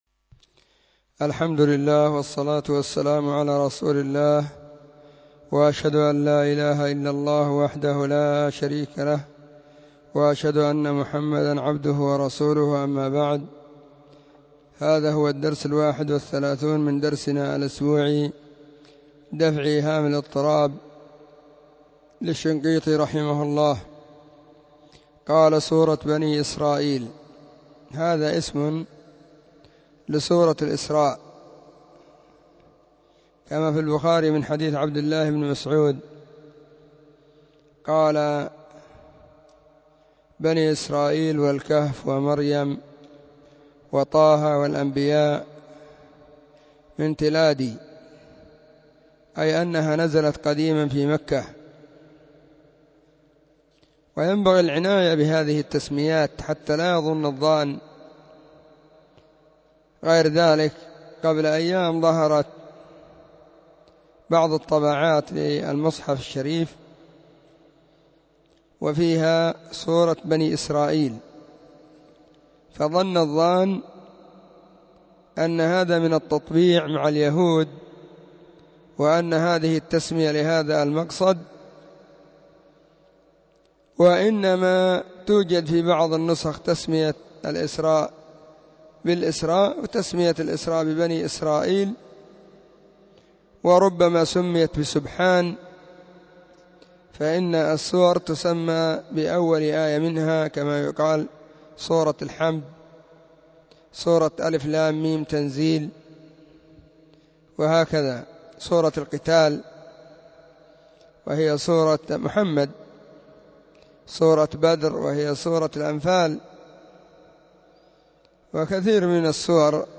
⏱ [بعد صلاة الظهر في كل يوم الخميس]
📢 مسجد الصحابة – بالغيضة – المهرة، اليمن حرسها الله.